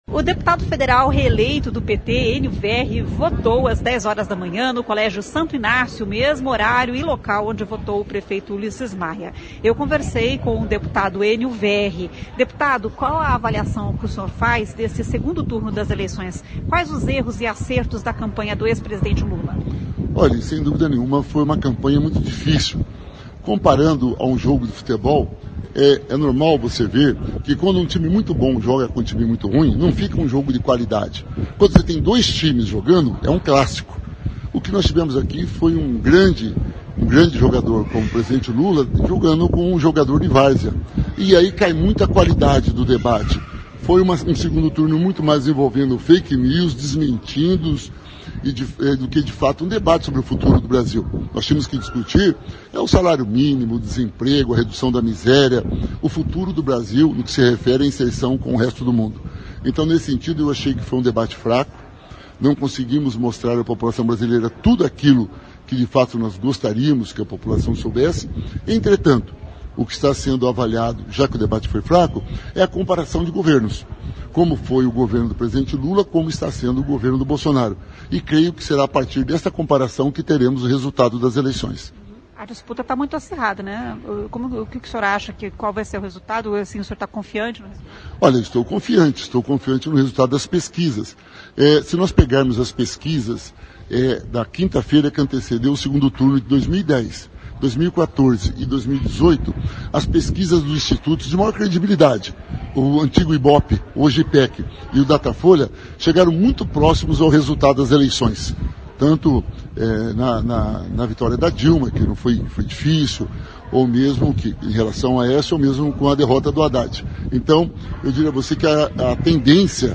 O deputado federal reeleito Ênio Verri (PT) faz uma análise da campanha e das pesquisas e diz estar confiante na vitória.